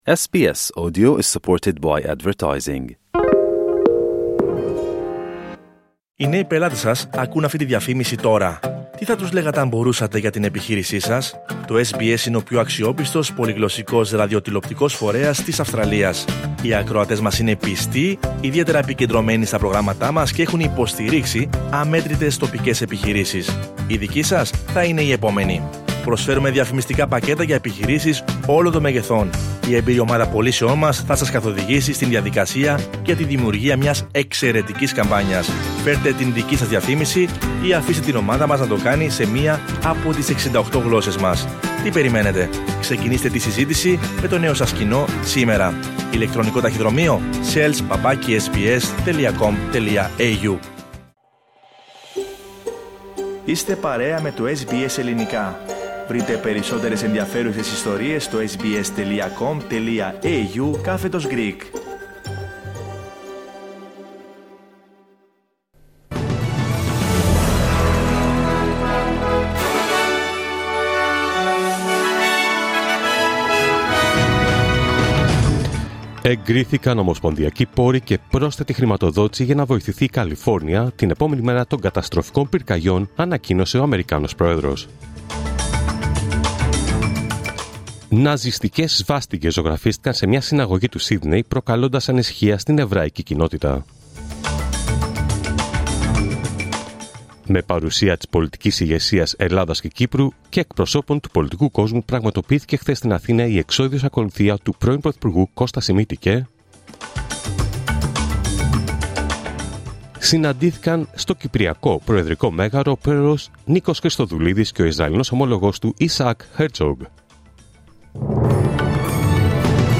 Δελτίο Ειδήσεων Παρασκευή 10 Ιανουαρίου 2025